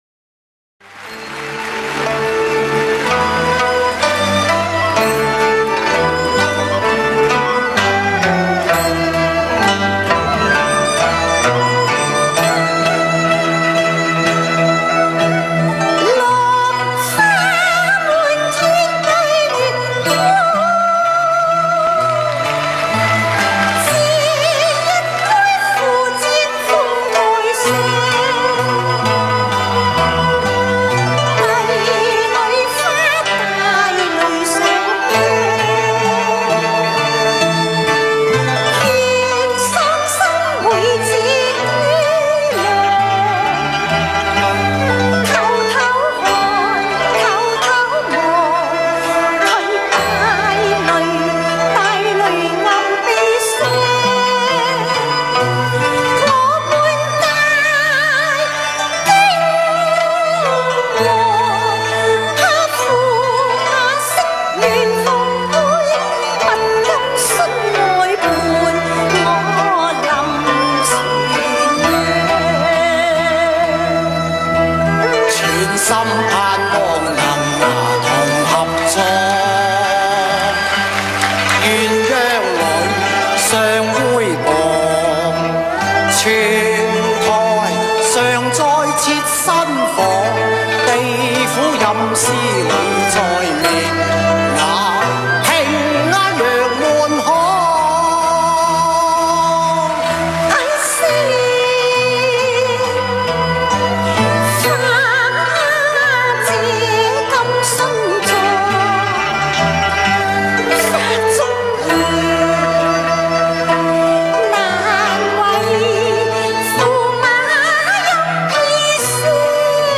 Chinese opera, Cantonese opera